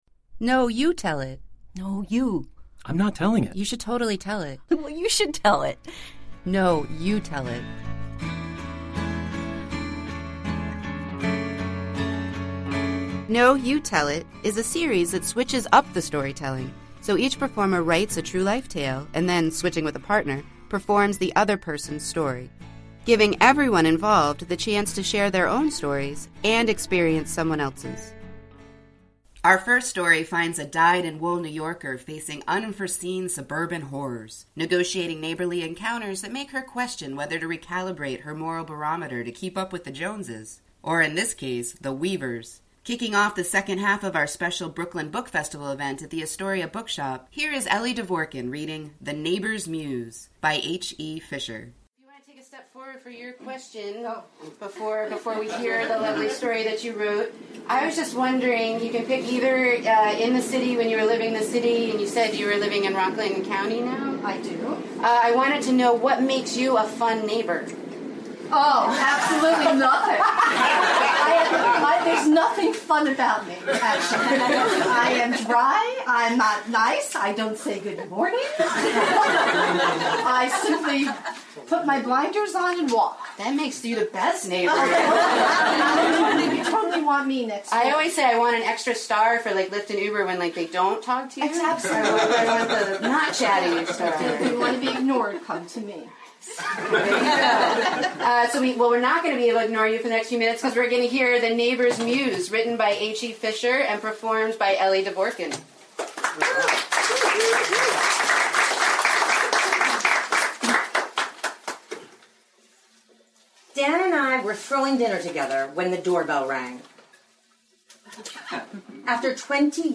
Stories performed live on September 17th, 2019.